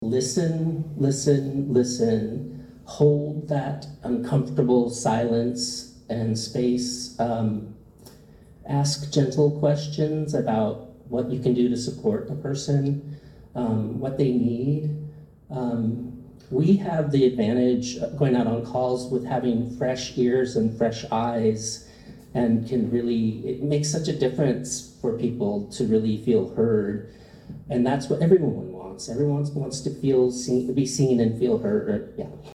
It was an evening of thought-provoking conversation and heart-felt storytelling at Garlington Health Center.